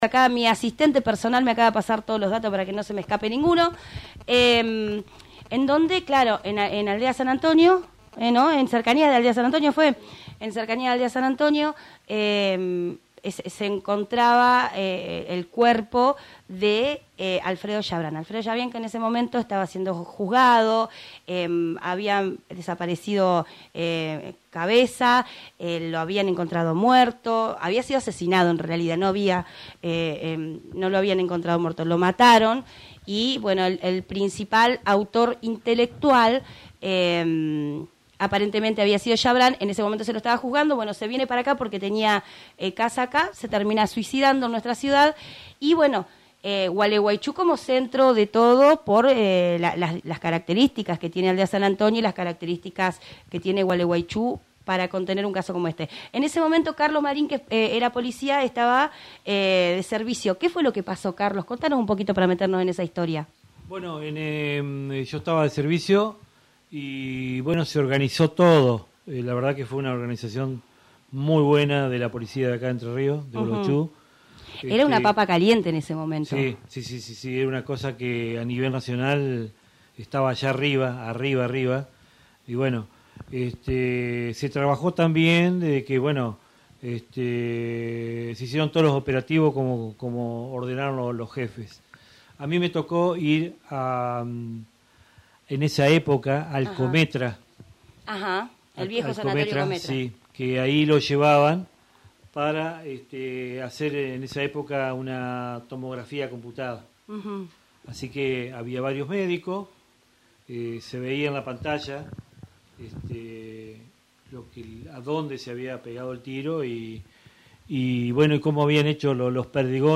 vecinos del barrio